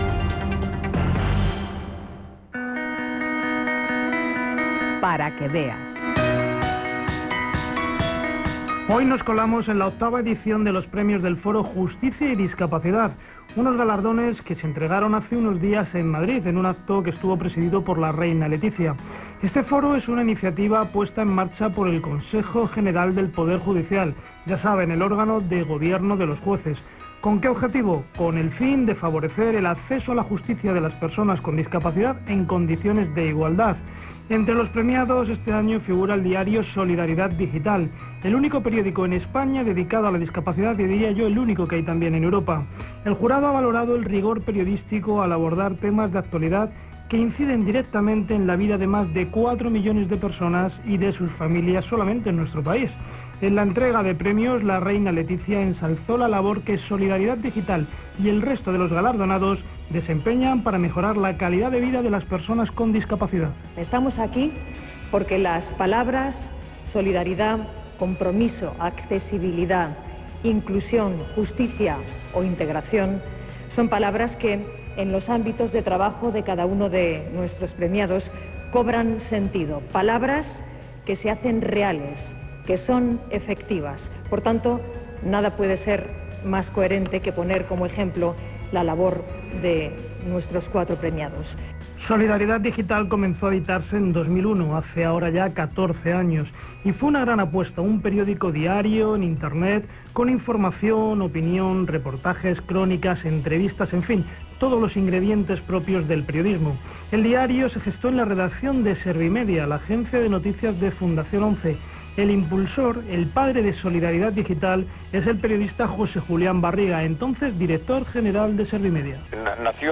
Información sobre la concesión del premio en Radio 5, con declaraciones de sus protagonistas